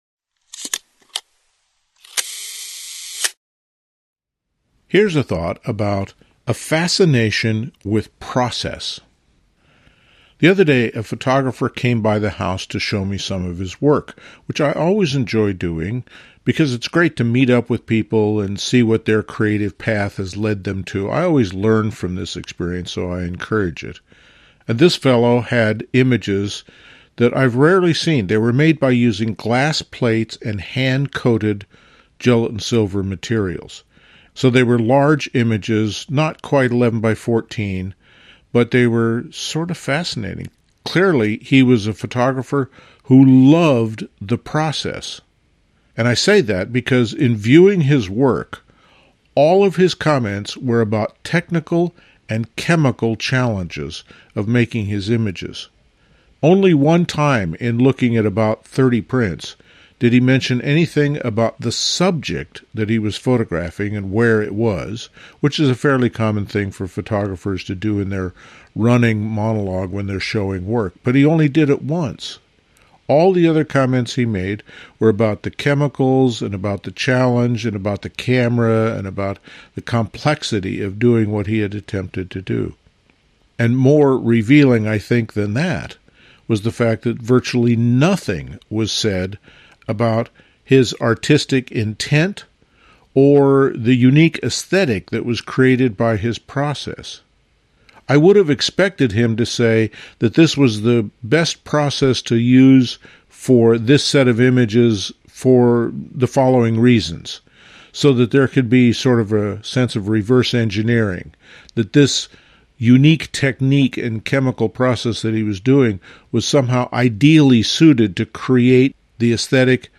These talks focus on the creative process in fine art photography.